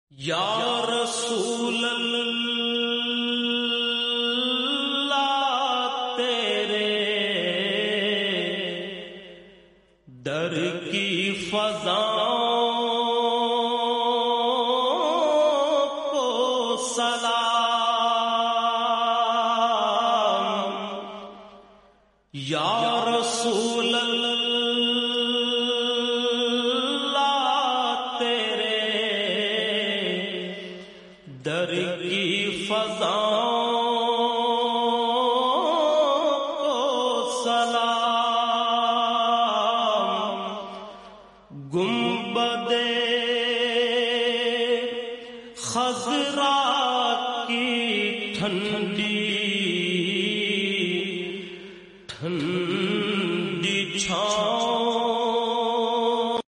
Naat
A heart-touching glimpse from the Rawdah Mubarak